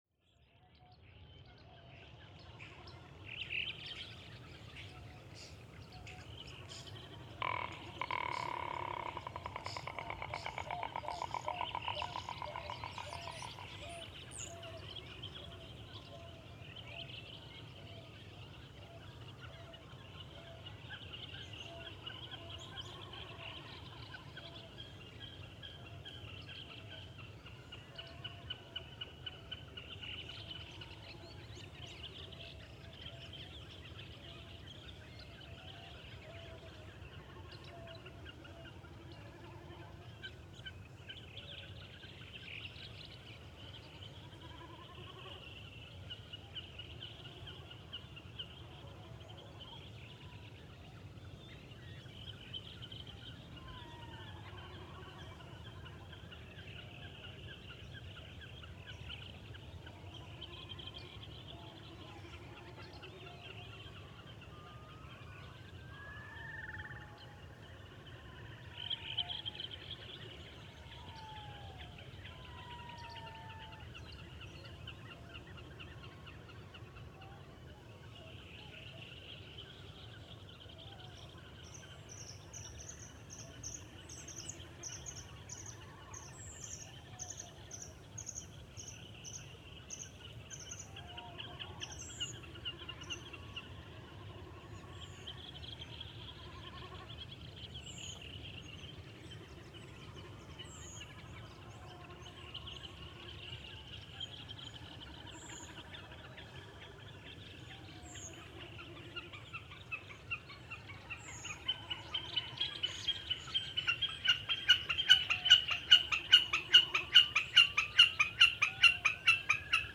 So in mid May the air is loaded with birds song.
What amazes me always is the fact that many of these birds are coming far distances from hot claimed continents like Africa and when they arrive they still have the power to fly around and sing almost 24/7 in several weeks. It was no exception to this on May 12, 2019 when I arrived at Stafholtstungur in SW of Iceland with my recording gear.